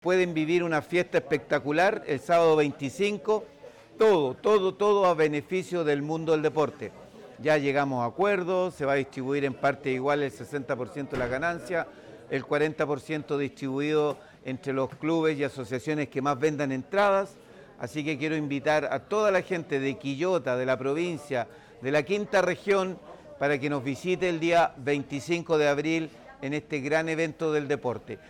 En ese contexto, el alcalde de la comuna, Luis Mella Gajardo, extendió la invitación a toda la comunidad a ser parte de esta actividad: